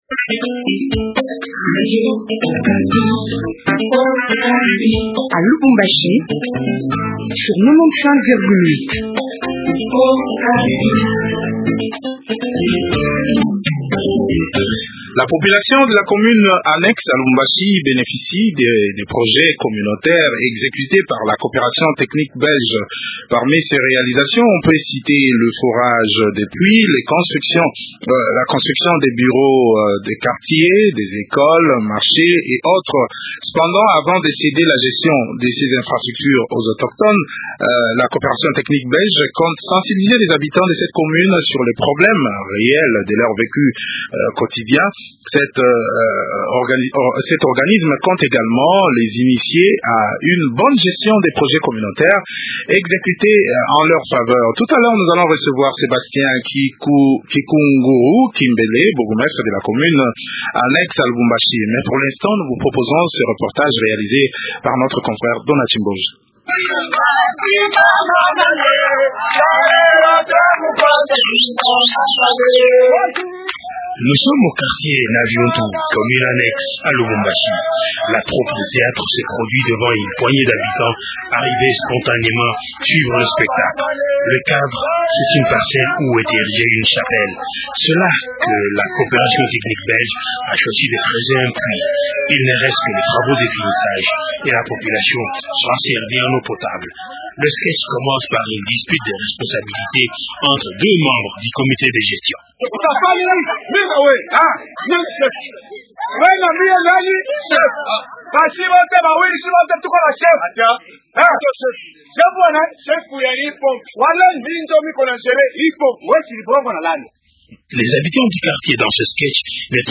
reçoivent M. Sébastien Kikunguru, bourgmestre de la Commune Annexe